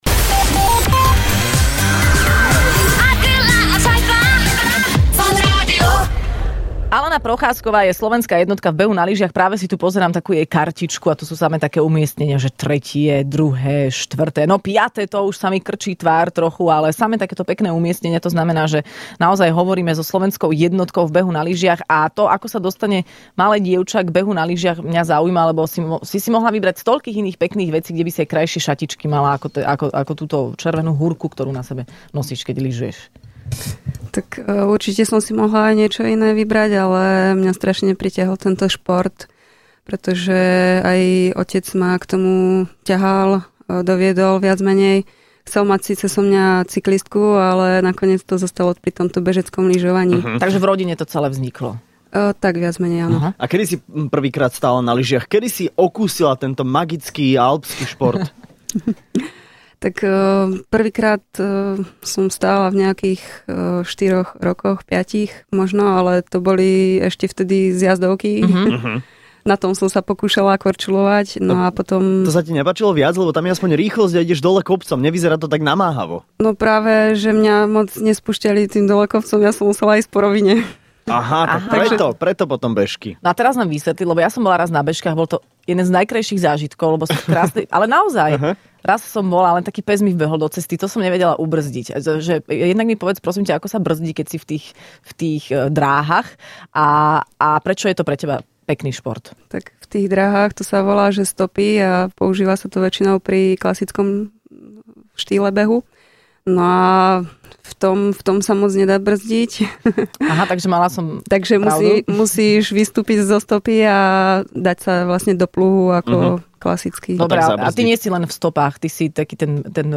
Hosťom v Rannej šou bola slovenská jednotka v behu na lyžiach - Alena Procházková